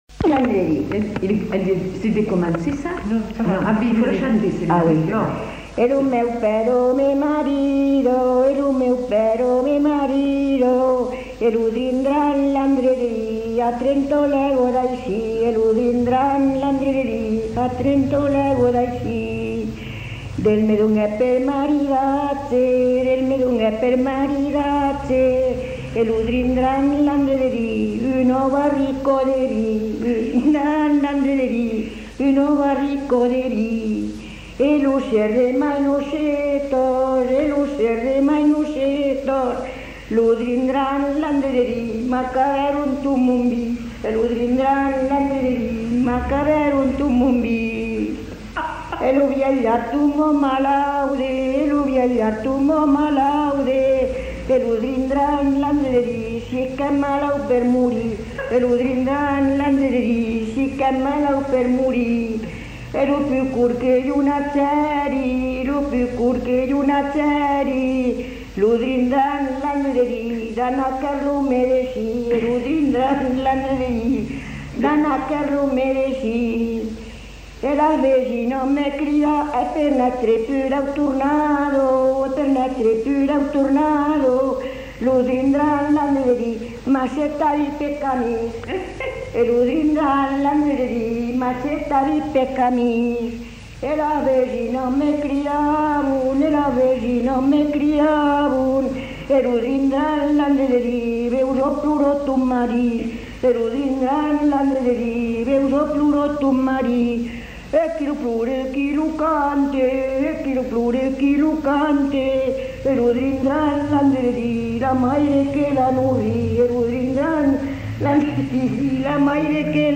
Lieu : Cancon
Genre : chant
Type de voix : voix de femme
Production du son : chanté
Danse : rondeau